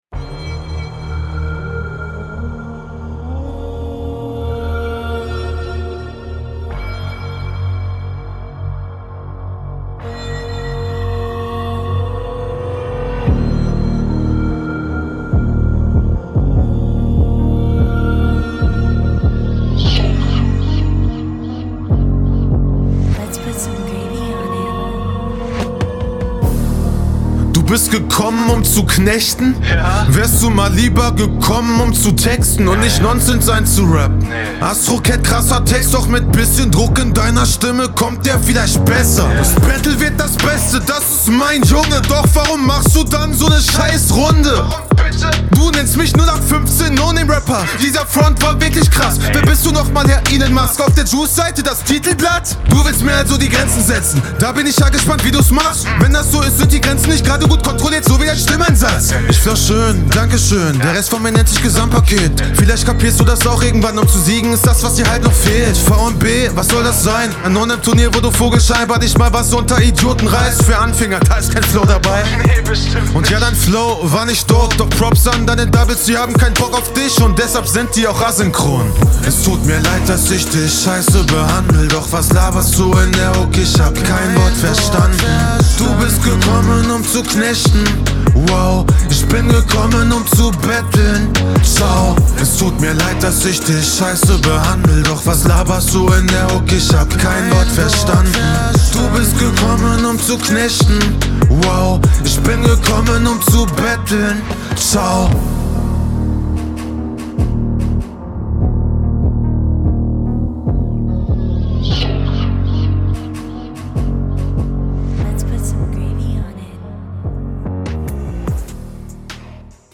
der Part gefällt mir klanglich hier sehr gut. deine Soundqualität ist besser und du rapst …